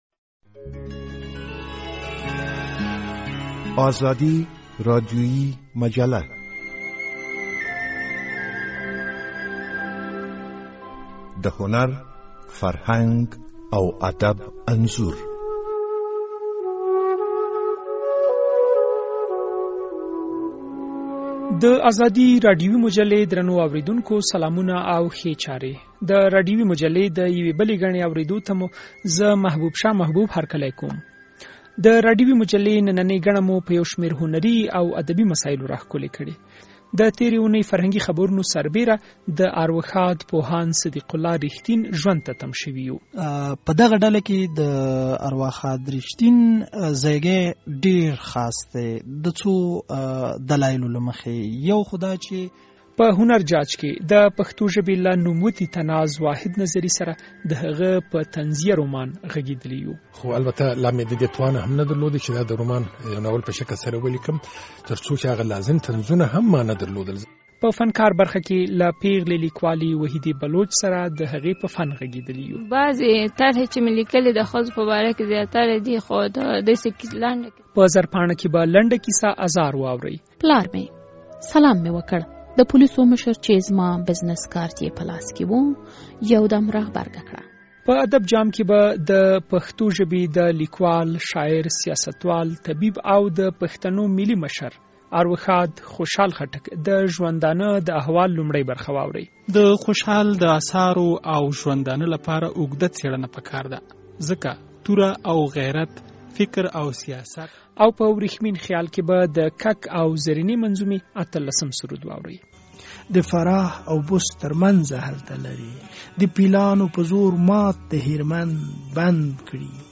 راډيویي ادبي مجله